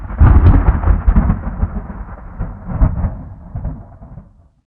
thunder27.ogg